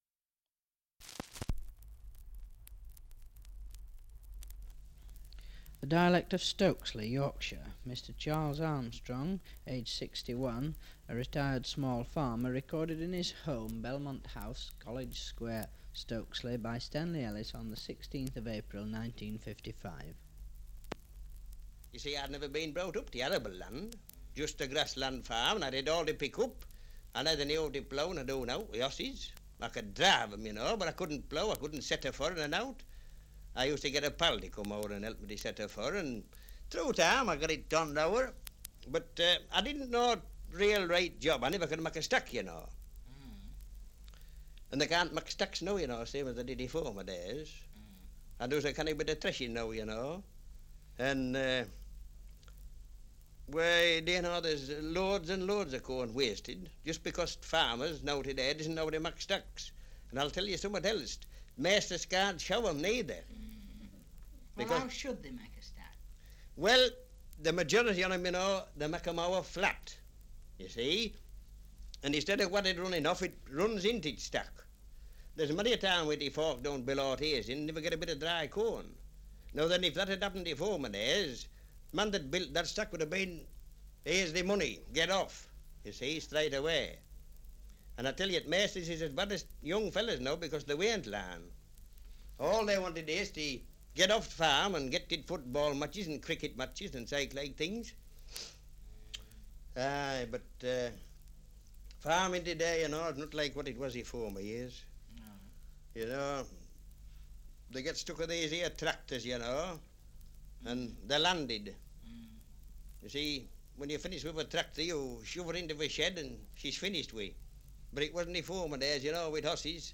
Survey of English Dialects recording in Stokesley, Yorkshire
78 r.p.m., cellulose nitrate on aluminium